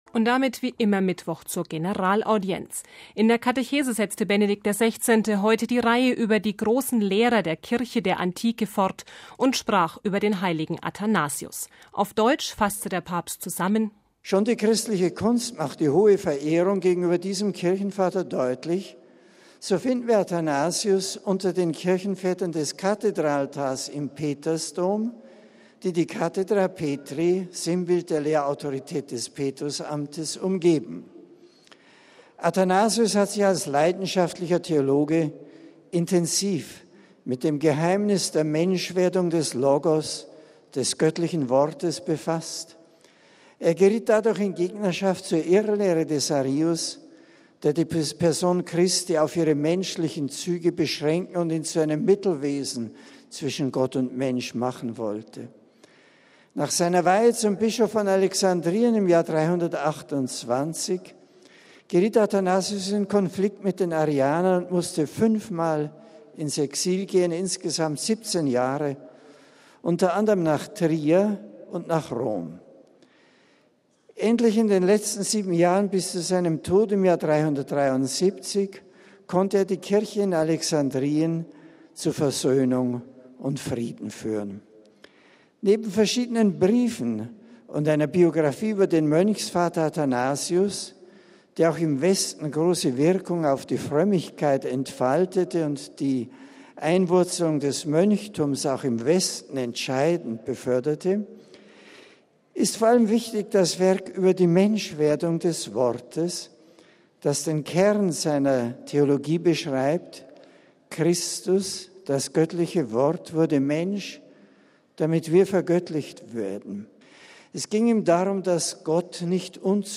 MP3 In der Katechese der Generalaudienz setzte Benedikt XVI. heute die Reihe über „die großen Lehrer der Kirche der Antike“ fort und sprach über den heiligen Athanasius.
Wegen der schwülen Hitze in Rom fand die Generalaudienz heute nicht auf dem Petersplatz statt.
Die Katechese und die Grüße an die Pilgergruppen folgten in der Audienzhalle.